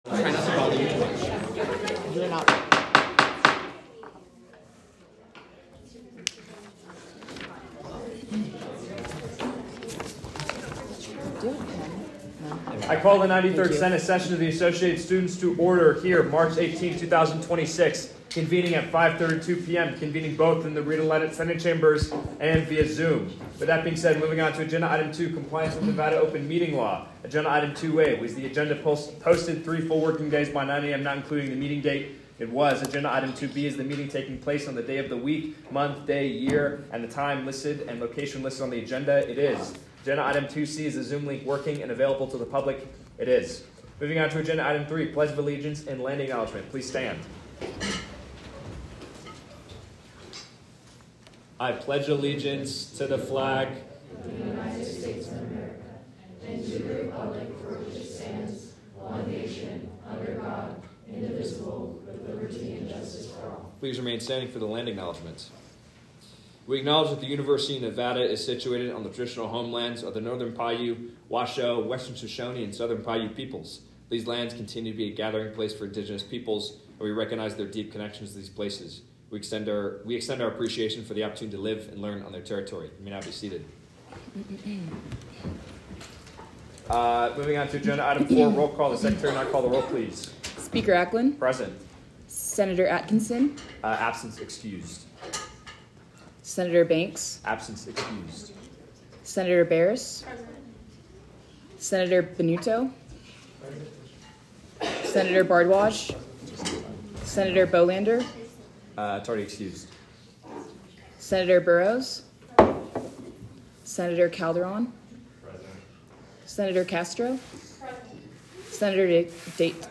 Meeting Type : Senate
Location : Rita Laden Senate Chambers